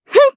Kirze-Vox_Attack1_jp.wav